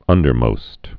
(ŭndər-mōst)